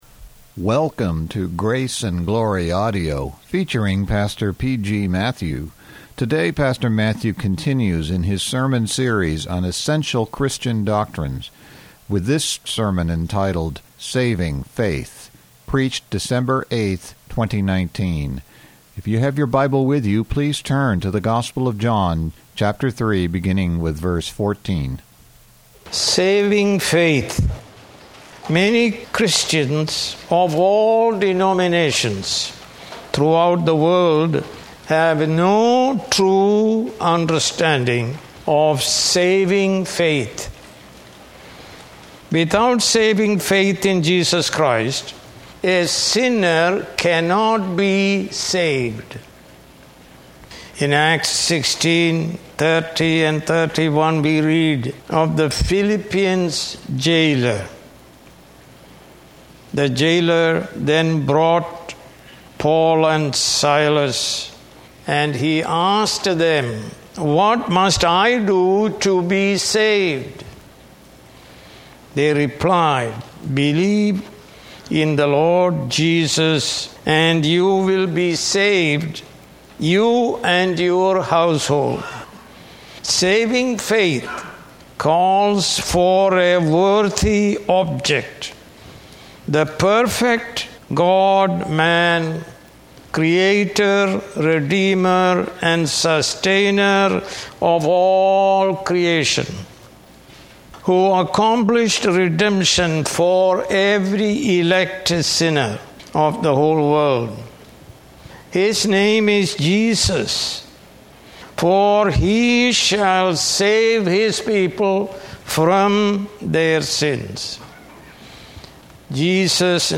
More Sermons